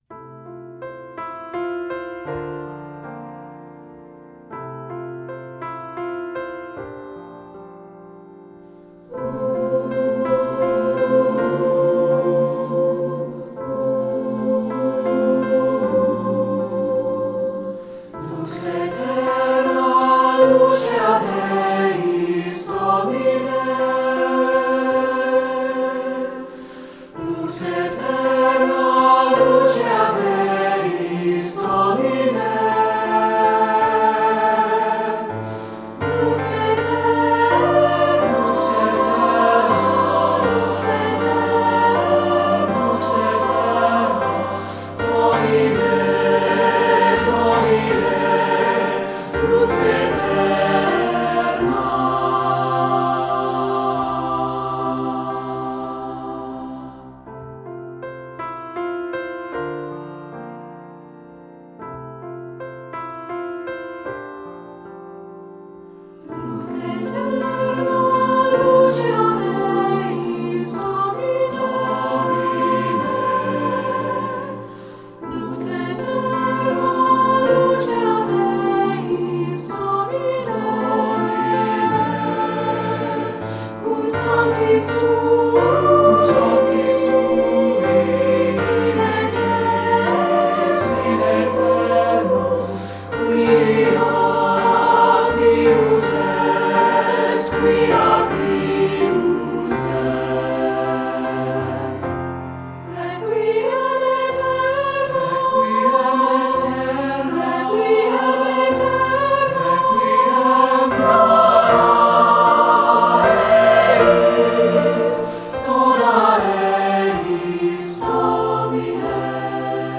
secular choral
SSA (SATB recording)